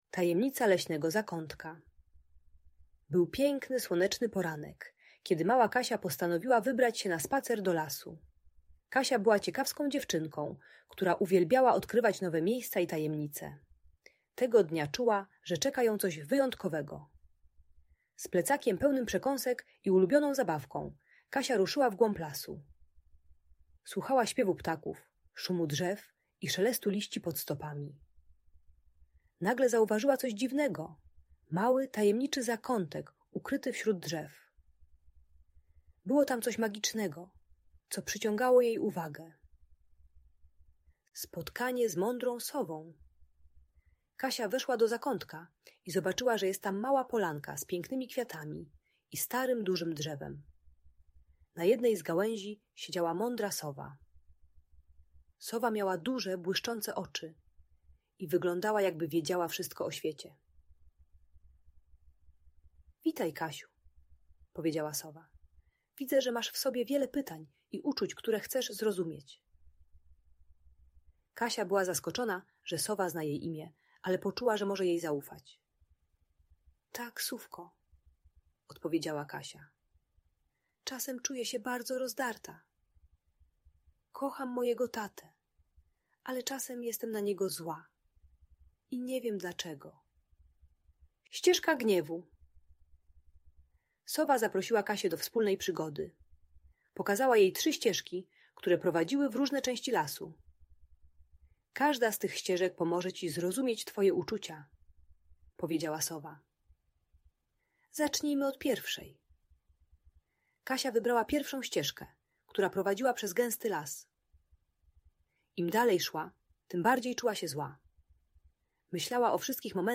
Tajemnica Leśnego Zakątka - Piękna Opowieść - Audiobajka